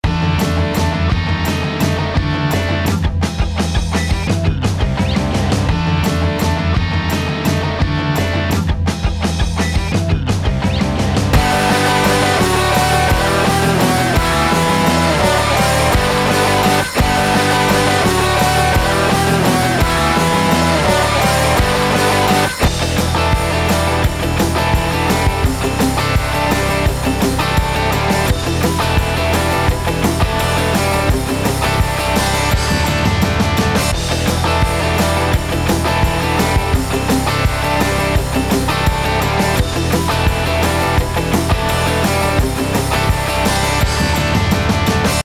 Hier zunächst das unbearbeitete Original, Loops aus Ueberschall Indie Rock: